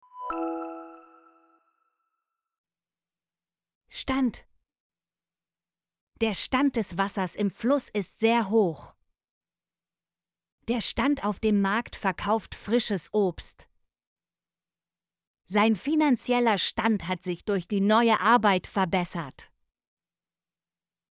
stand - Example Sentences & Pronunciation, German Frequency List